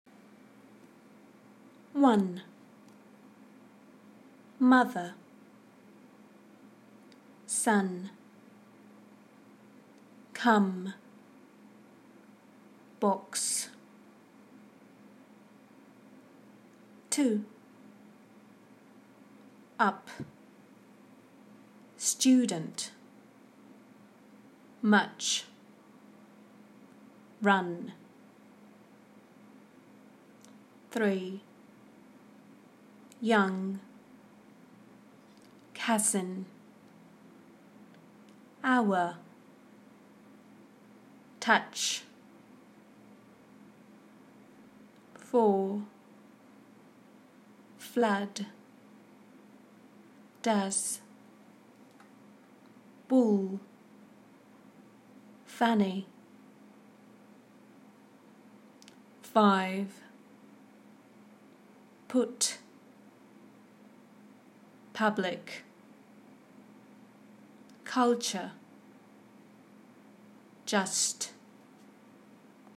PRONUNCIATION: Pronouncing /ʌ/